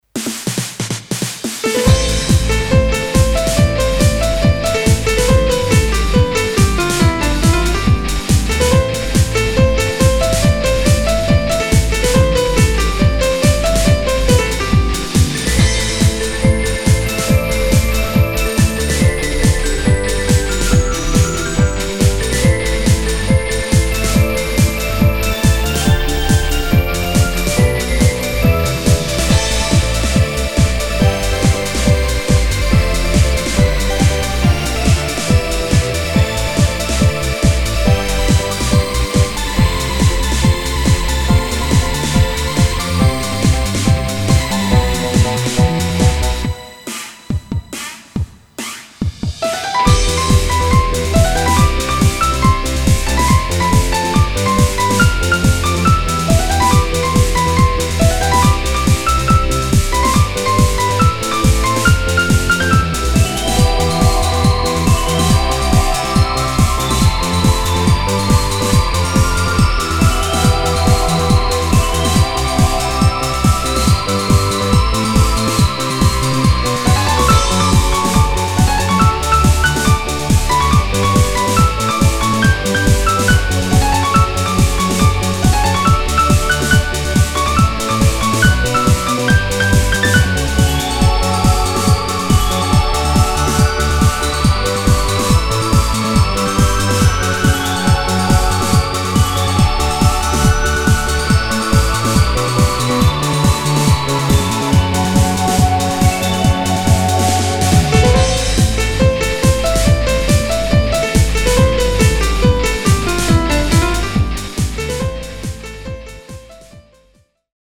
フリーBGM バトル・戦闘 4つ打ちサウンド
フェードアウト版のmp3を、こちらのページにて無料で配布しています。